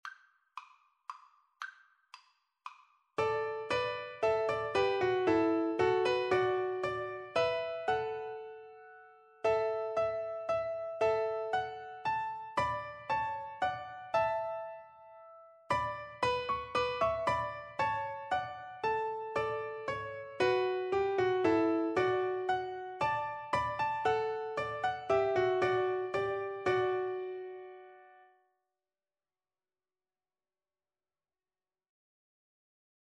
Free Sheet music for Piano Four Hands (Piano Duet)
D major (Sounding Pitch) (View more D major Music for Piano Duet )
3/4 (View more 3/4 Music)
Traditional (View more Traditional Piano Duet Music)